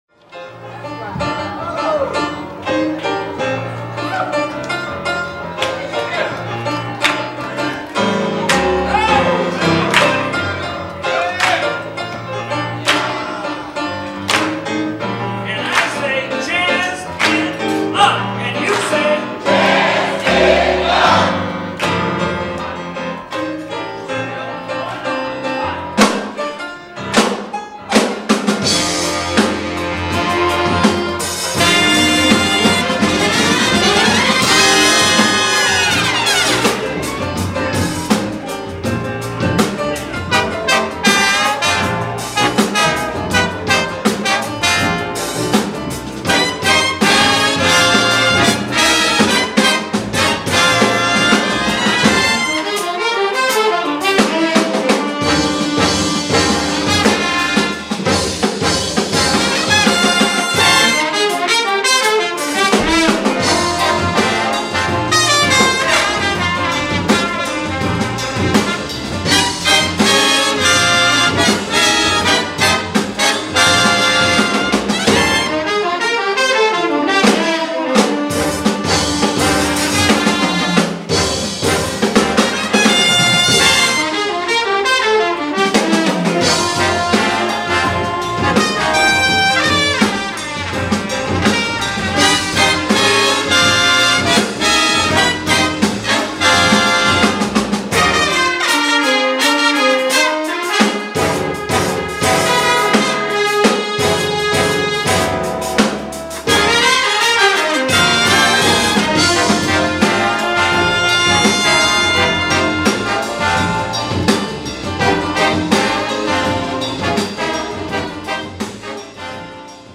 Category: big band
Style: Latin funk
Solos: open
Instrumentation: big band (4-4-5, rhythm (4)